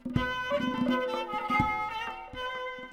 Mirroring technique in tombak-kamancheh pairing.